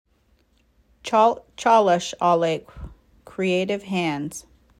Named after the Muckleshoot Indian Tribes word for ‘Creative Hands’ the CaleCalsika (“chal-chalish-ah-lake”) Gallery is a revolving showcase of 2D and 3D works from artists throughout the Pacific Northwest.
Below is an Muckleshoot language and audio representation of the word CaleCalsika :